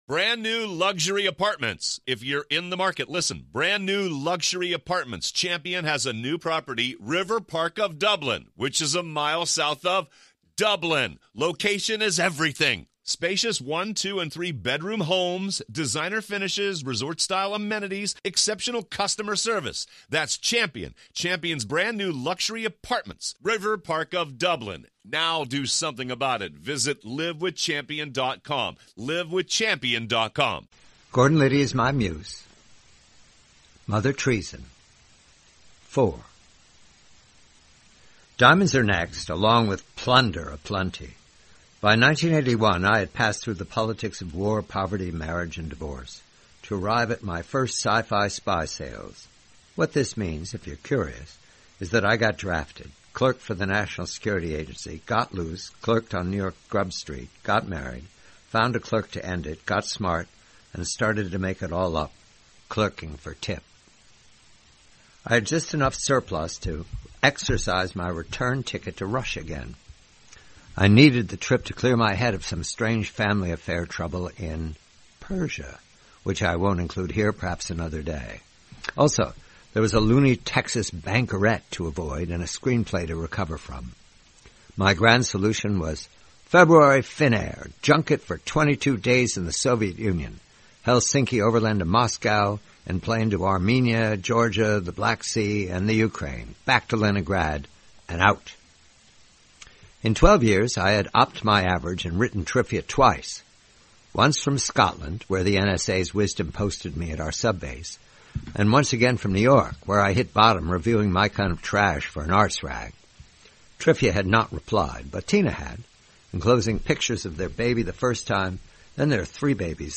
4/7: 2022: Is the KGB at the final brink? : 4/7: 1959: "Mother Treason," a story from the collection, "Gordon Liddy Is My Muse," by John Calvin Batchelor. Read by John Batchelor.